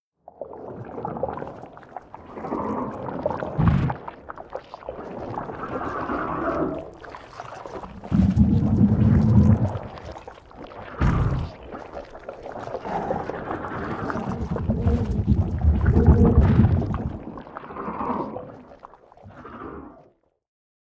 spawner.ogg